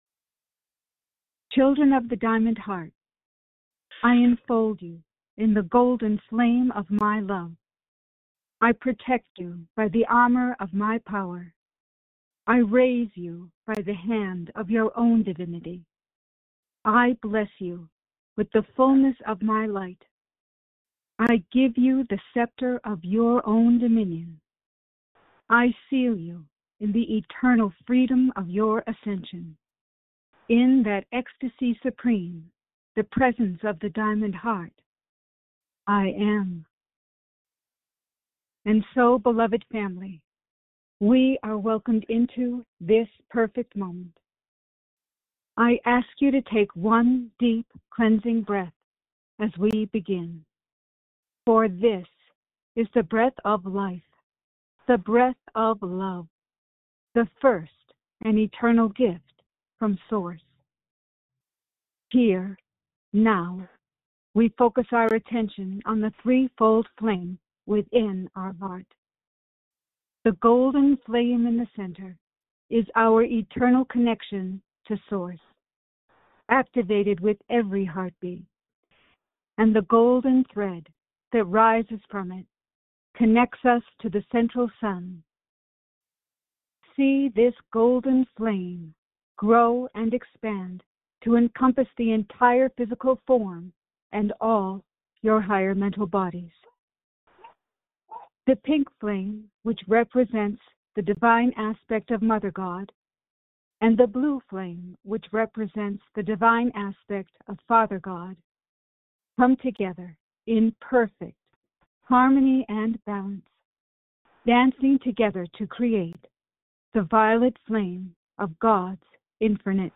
Audio Recording Meditation